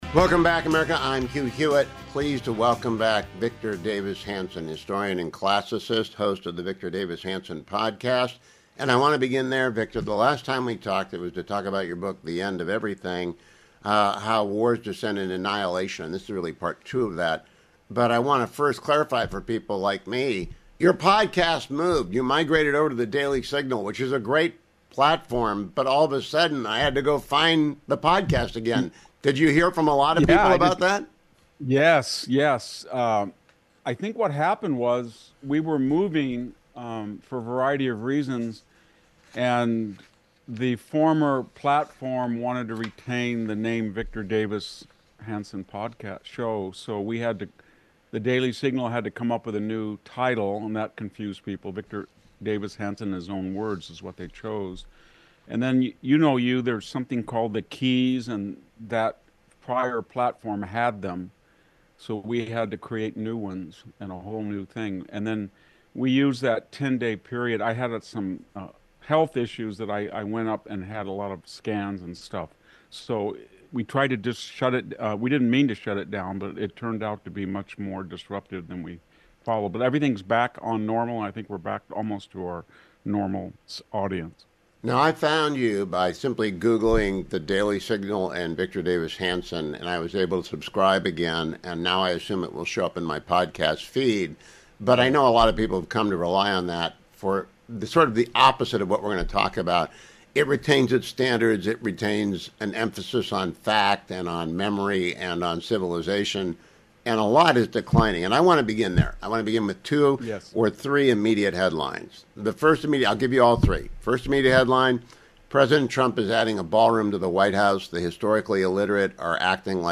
Historian and classicist Victor Davis Hanson joined me to discuss the collapse of the domestic political discourse and the hard left lurch of the Democrats: